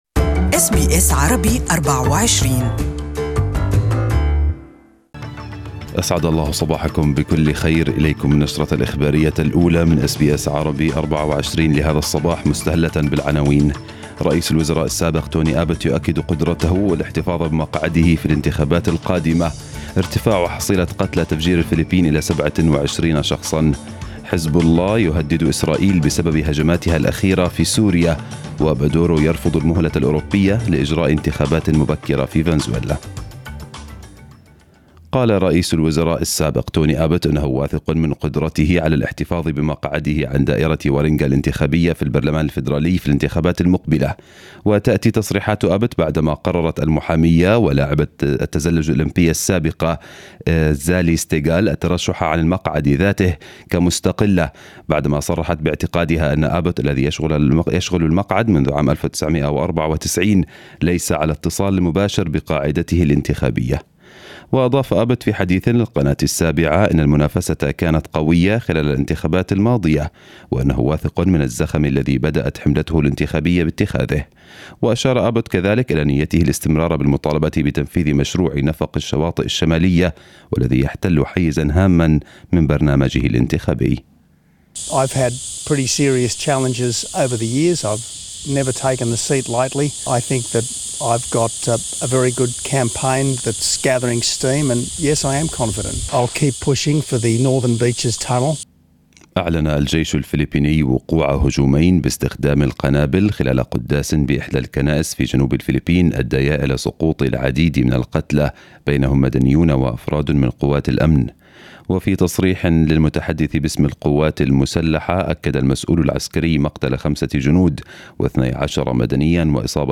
News bulletin in Arabic